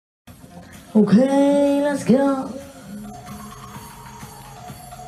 Ok Let's Go Meme Sound sound effects free download
Ok Let's Go Meme Sound Effect